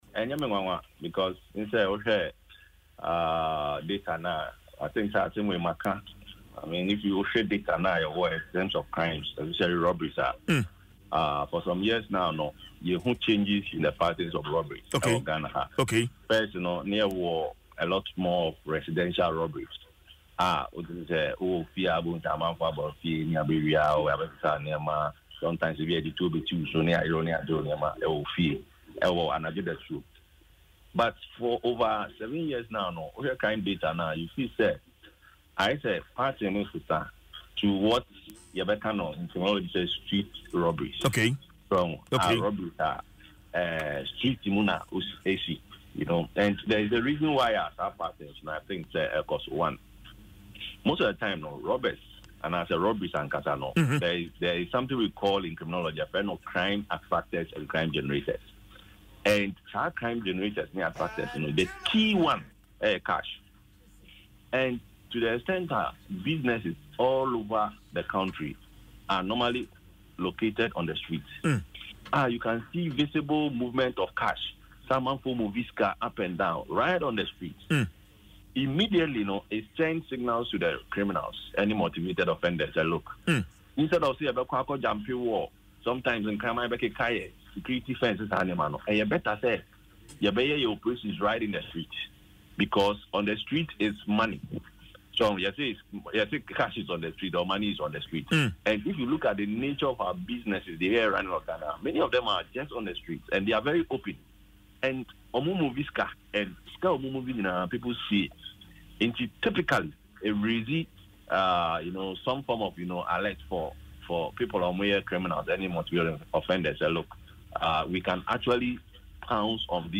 In an interview on Adom FM’s Dwaso Nsem, he said that initially, most robberies occurred in homes, but now they are happening on the streets in broad daylight.